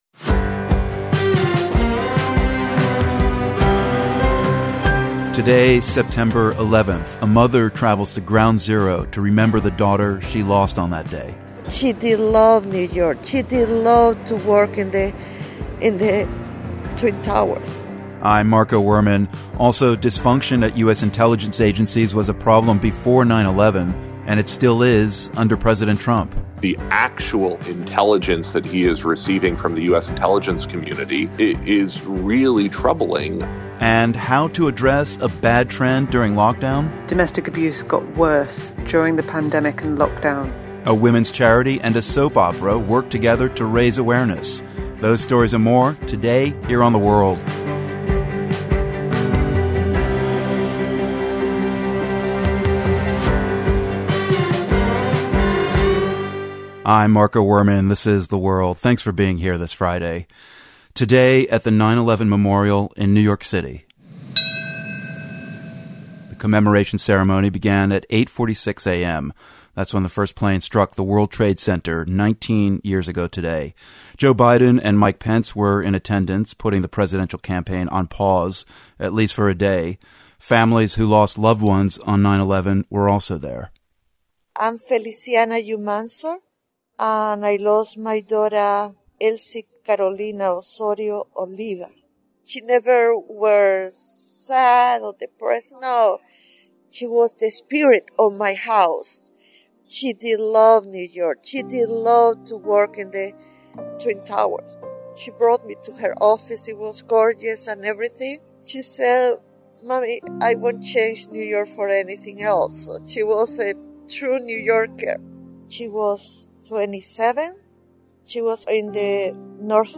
Nineteen years ago today, dysfunction at US intelligence agencies stymied efforts to prevent the deadliest attack on American soil in history. Host Marco Werman speaks with a national security expert. And, thousands of migrants on the Greek island of Lesbos are without shelter, after the refugee camp where they were staying burned down.